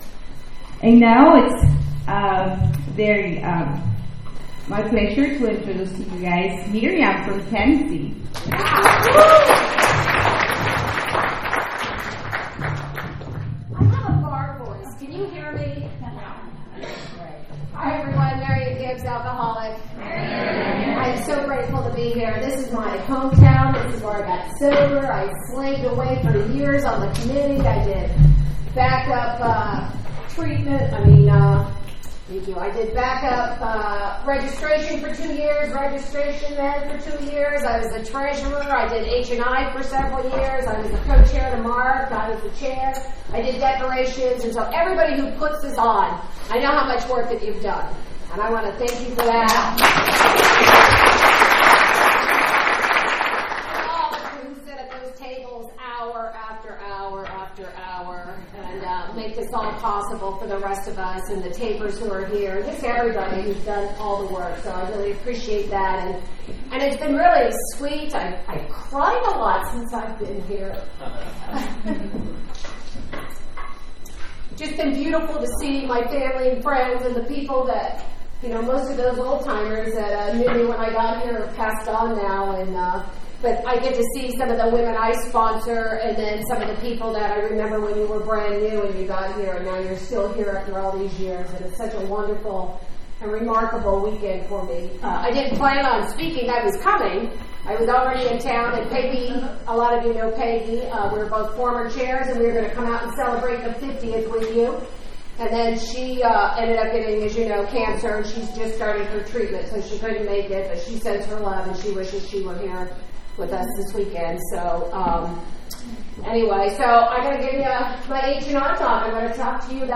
50th Annual Antelope Valley Roundup